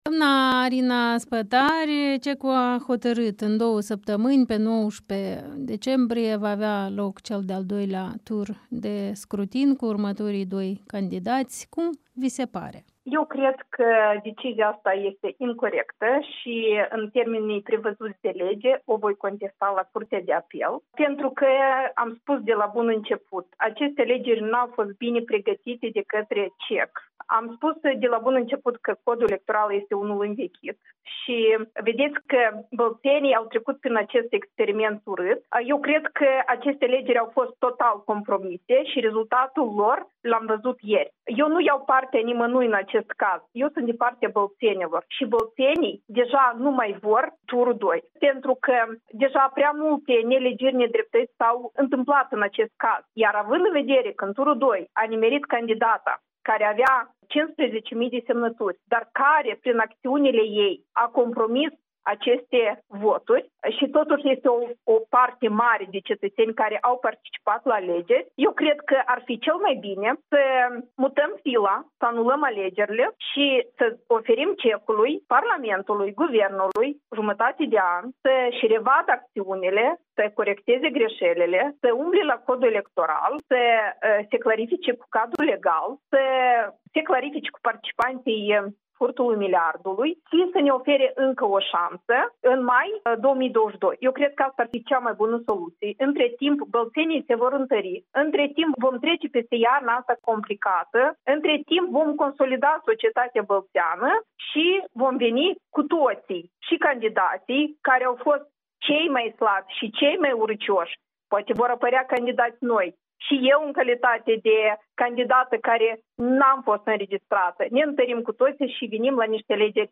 Interviu cu Arina Spătaru, fostă parlamentară DA, de la Bălți